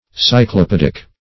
Cyclopedic \Cy`clo*ped"ic\ (s?`kl?-p?d"?k or -p?"d?k), a.